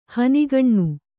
♪ hanigaṇṇu